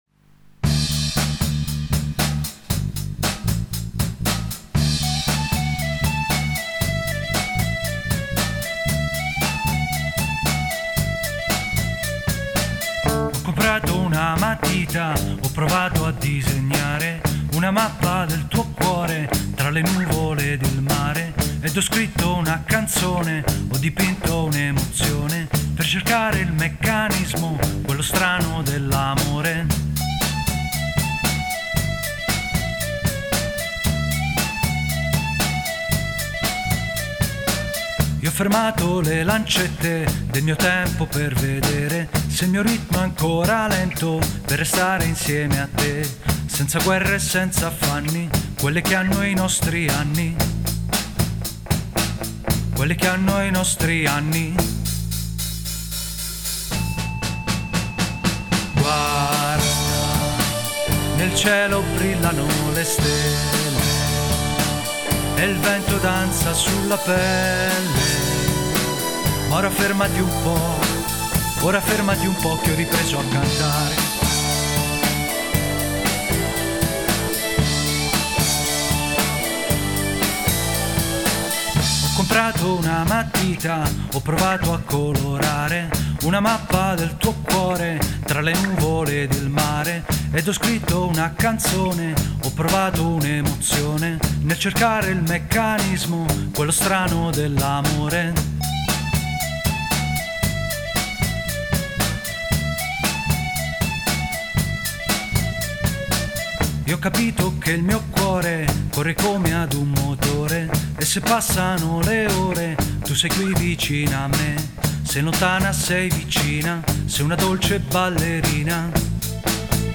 GenerePop / Musica Leggera